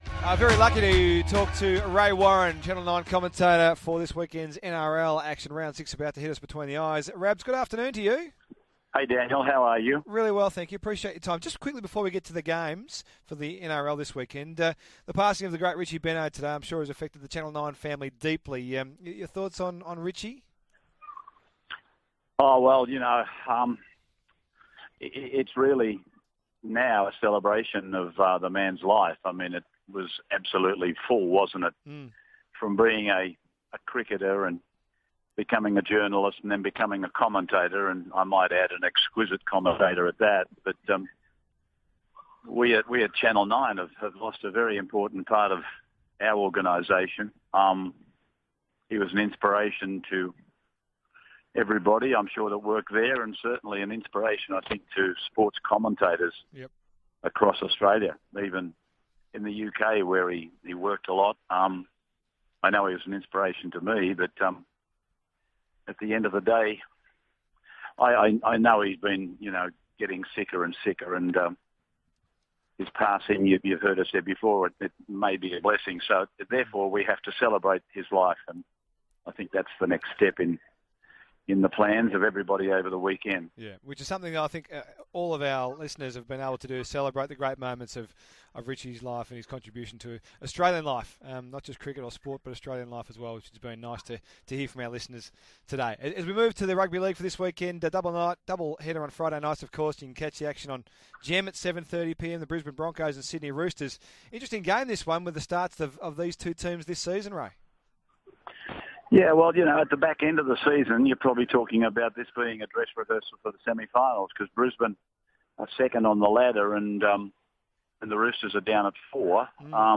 Channel Nine commentator Ray Warren joins Daniel Harford to pay tribute to Richie Benaud and preview Round 6 of the NRL.